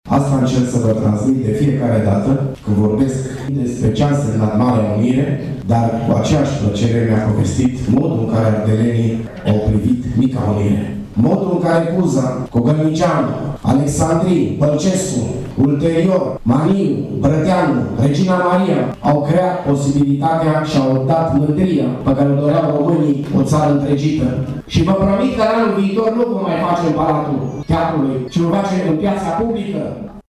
În discursul său de pe scena Teatrului, primarul Tg. Mureșului, Dorin Florea, s-a referit la lecția marilor înaintași, care au făurit întâi Unirea Principatelor, apoi Marea Unire de la 1918: